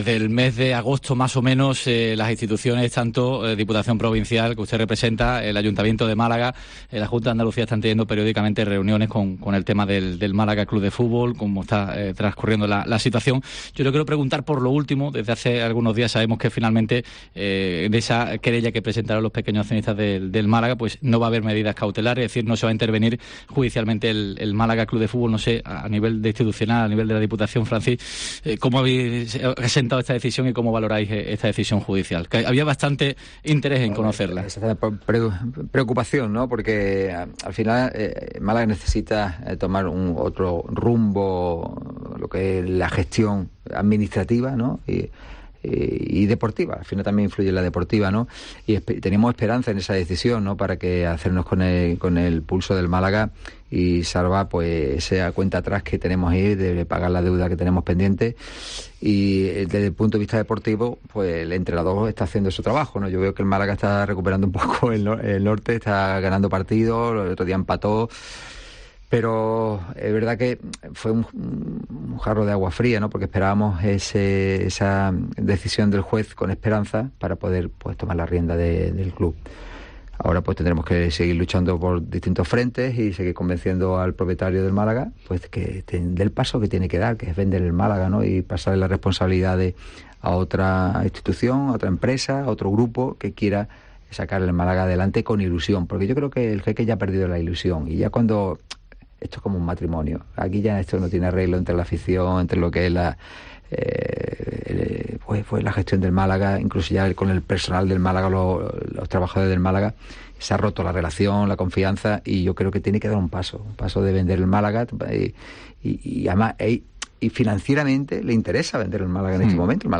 El presidente de la Diputación Provincial de Málaga, Francisco Salado, pasó por los micrófonos de "Herrera en COPE Más Málaga" y analizó la situaicón que vive el Málaga C.F. y como ha sentado la decisión de la titular del juzgado de instrucción número 14 de Málaga de no aplicar medidas cautelares contra la gestión de Al Thani: "Estamos muy preocupados por el Málaga C.F. El club necesita tomar otro rumbo en la gestión administrativa y deportiva.